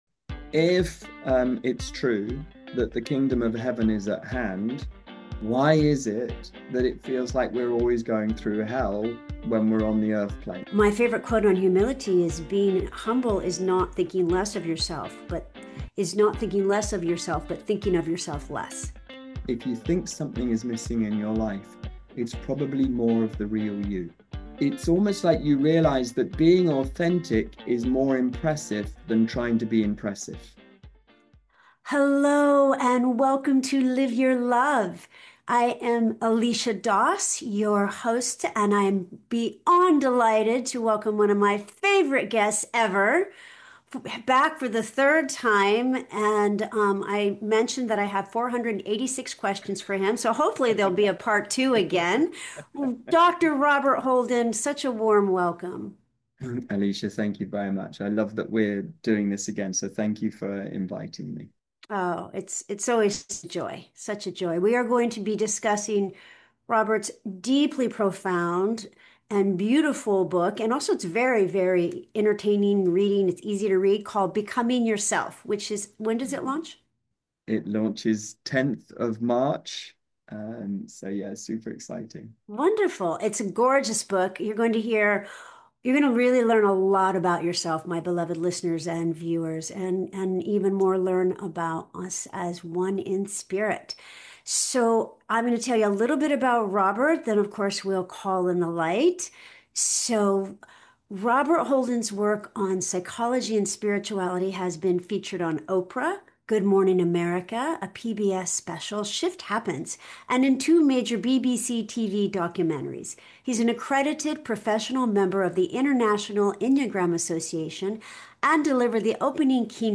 Dr. Robert Holden (Hay House) is back on Live Your Love for the THIRD time—and this conversation just keeps getting richer.
A closing prayer + meditation to release striving and return to presence, love, and remembrance